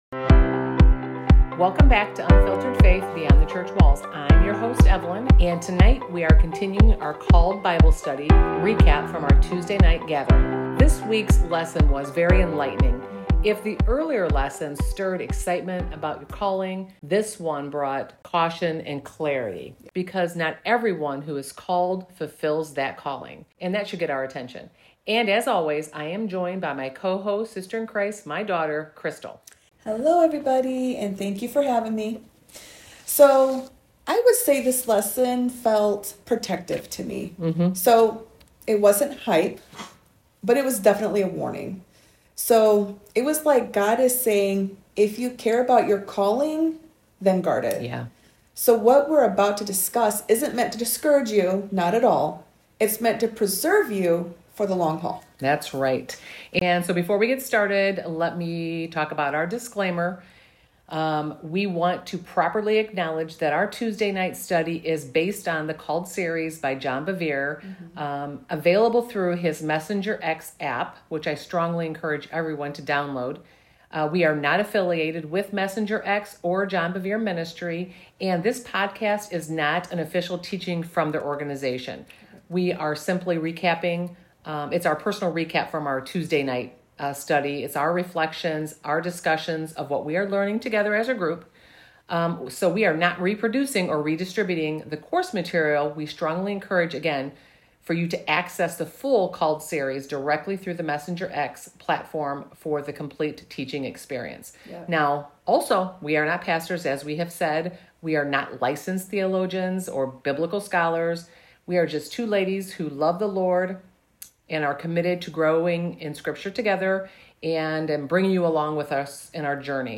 This episode is a personal recap and discussion from our Tuesday night Bible study at Beyond the Church Walls Ministry.